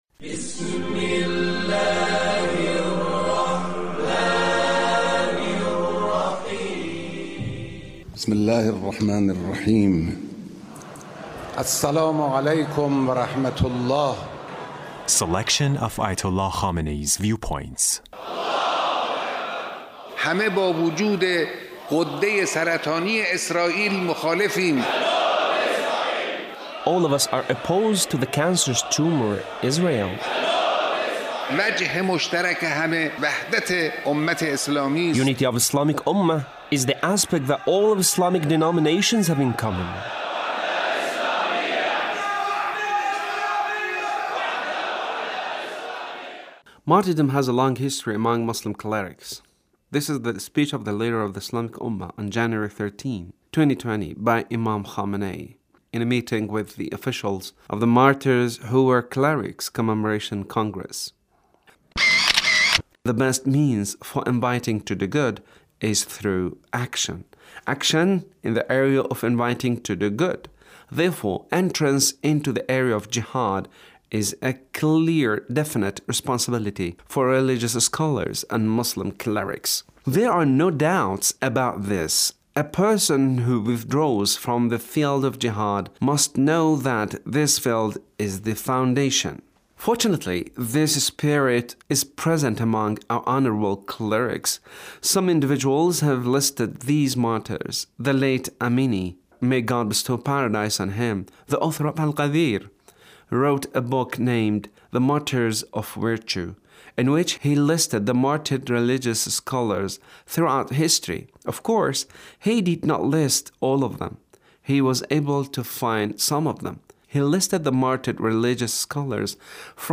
The Leader's speech on Quds Day